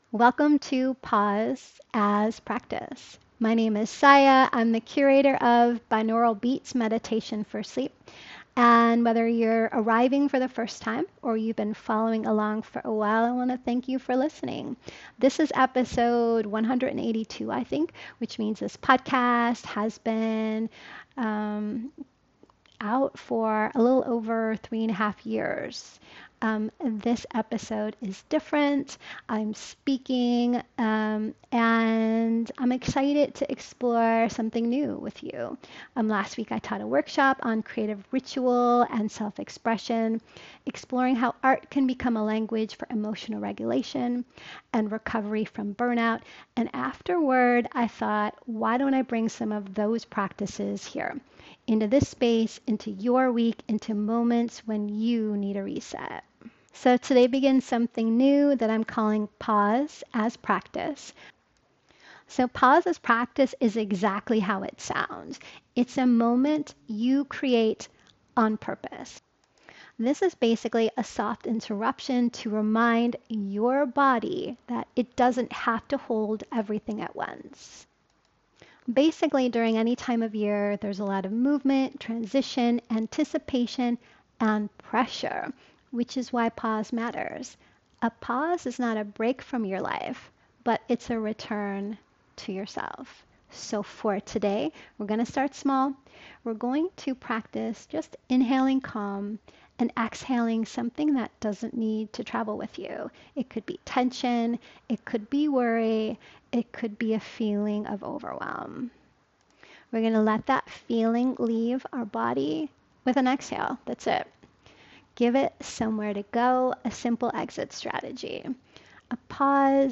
Binaural Beats Meditation for Sleep
This podcast is part of Ritual Era Meditation — a guided ritual experience